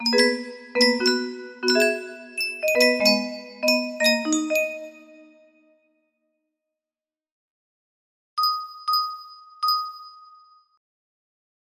Unknown Artist - Untitled music box melody
Full range 60